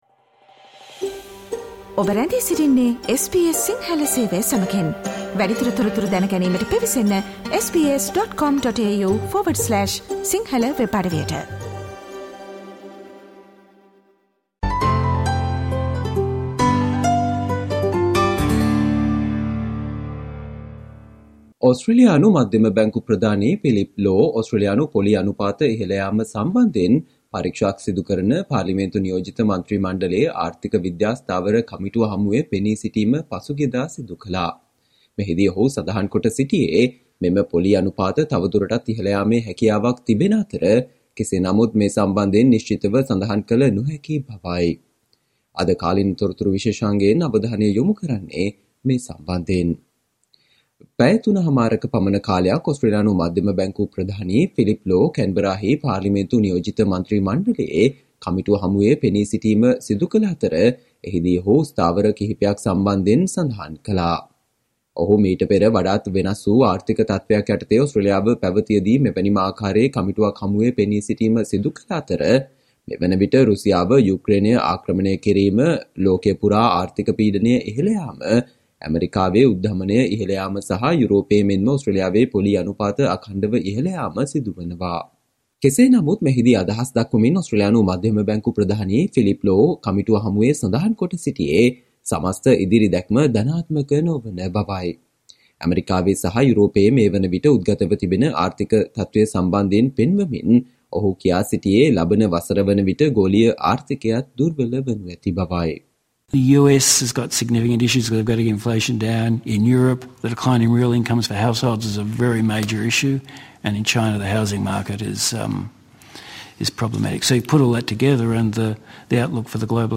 Today - 20 September, SBS Sinhala Radio current Affair Feature on RBA governor Phillip Lowe made several agreements at the parliamentary inquiry